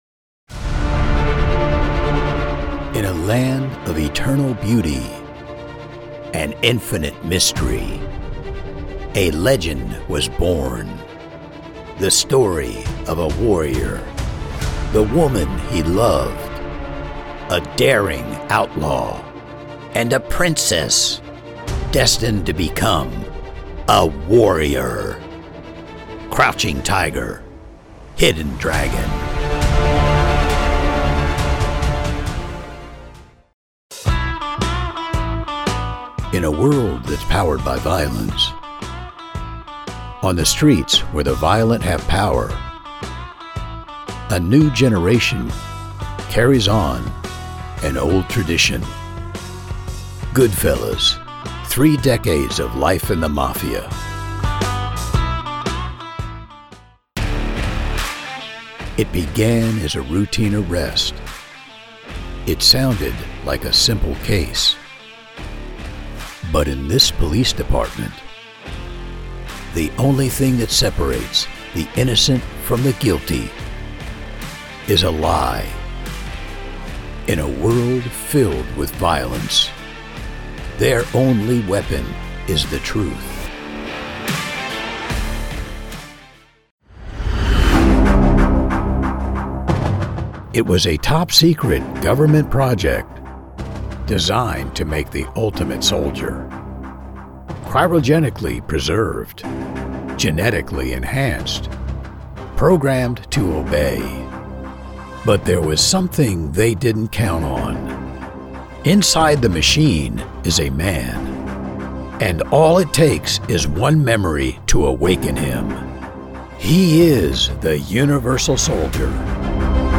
Movie Trailer Reel
Narrator, Dangerous, Deep, Evil, Explosive, Forceful, Gravelly, Gritty, Informative, Low Pitched, Manly/Masculine, Movie Trailer, Cynical, Ominous, Reassuring, Rugged, Skeptical, Strong, Trustworthy, Voice Of God, Announcer, Blue Collar, Abrupt, Aggressive, Angry, Attractive, Authoritative, Big, Bold, Booming, Bossy, Breathy, Burly, Charismatic, Clear, Compassionate, Condescending, Confident
Middle Aged
My studio is equipped with a RODE 5th Generation NT Mic, a VOLT 1 Audio Interface using Audacity DAW.